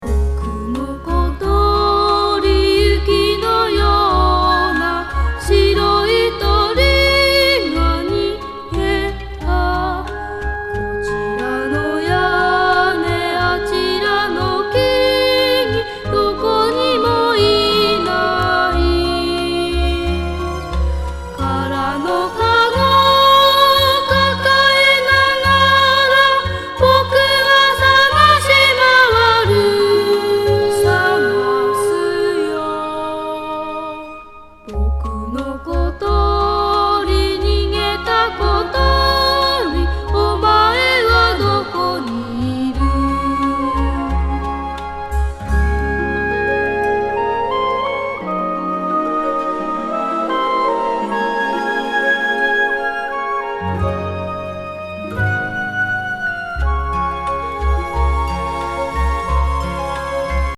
美少年愛な世界観をストイックに表現したイメージ・レコード